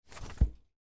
Book Close Effect